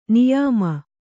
♪ niyāma